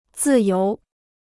自由 (zì yóu): freedom; liberty; free; unrestricted.